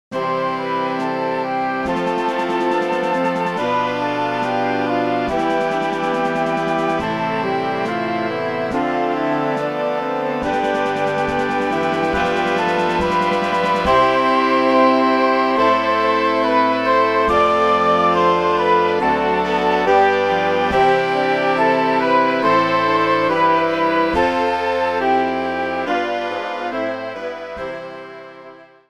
Kościelna